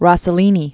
(rôsə-lēnē, rŏsə-, rōssĕ-), Roberto 1906-1977.